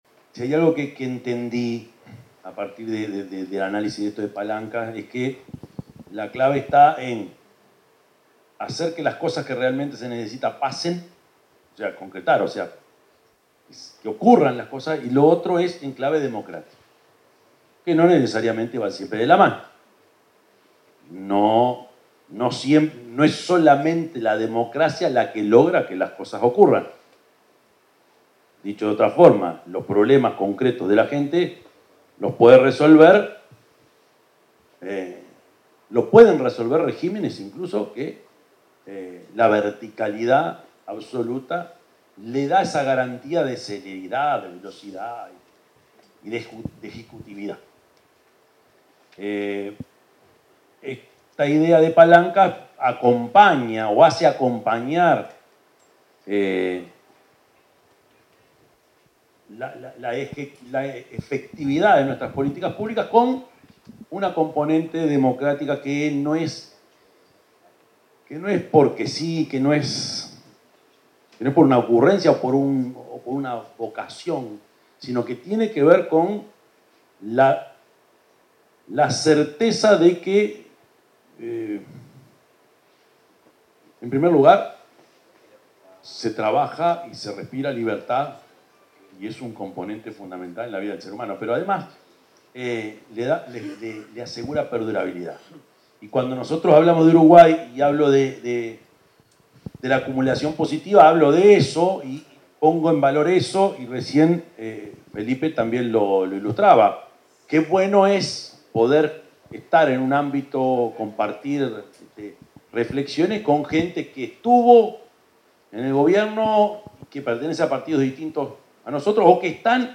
Palabras del presidente de la República, Yamandú Orsi